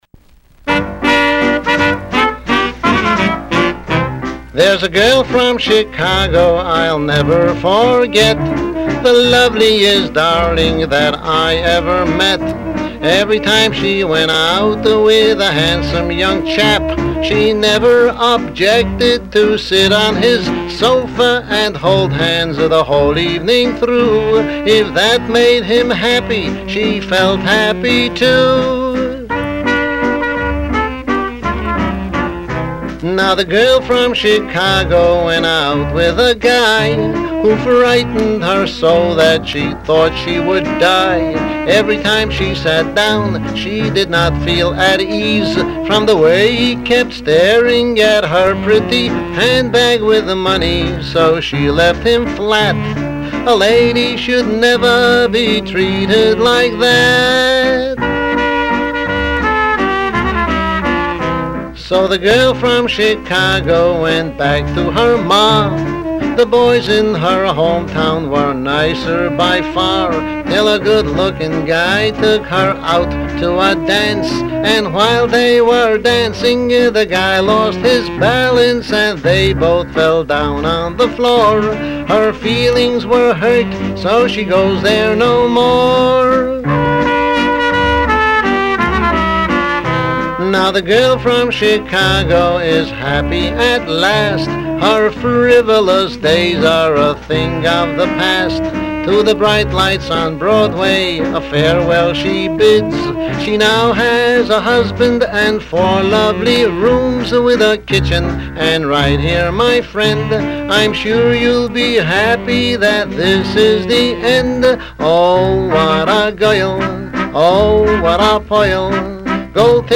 double entendre party record